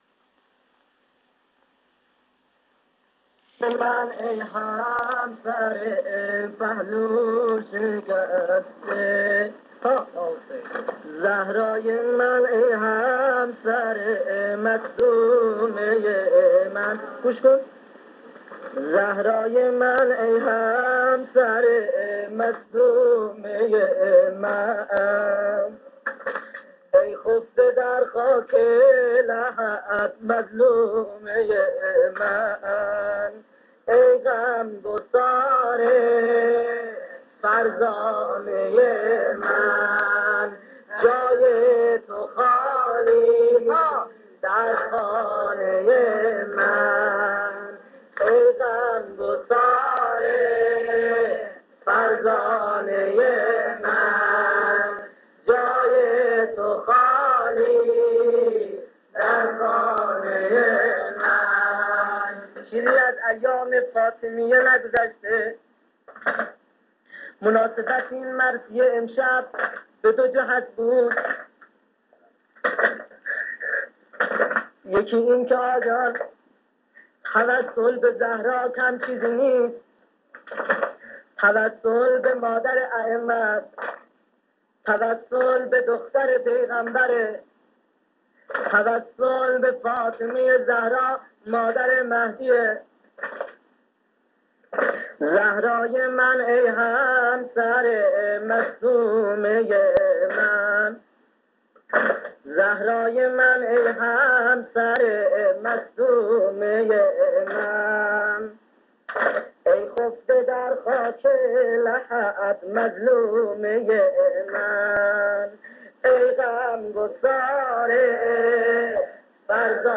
صوت/ قرائت قرآن